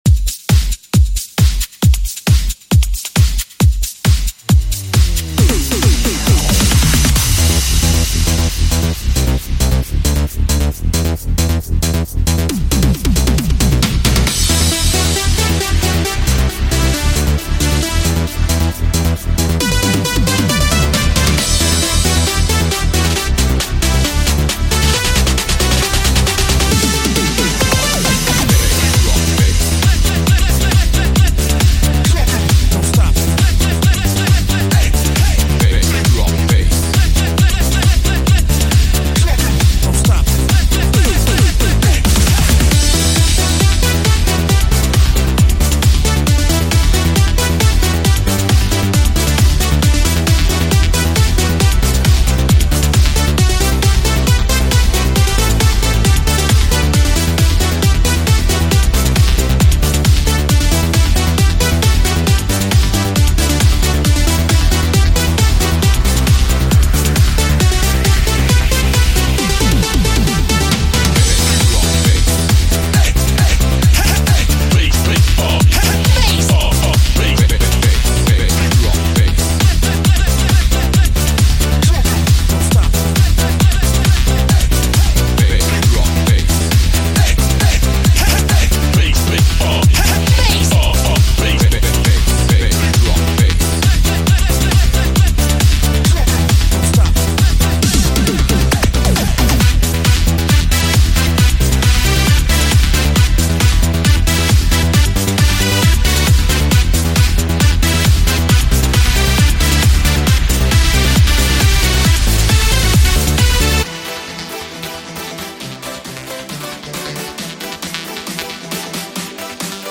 Generation_90x__Eurodance__
Generation_90x__Eurodance__.mp3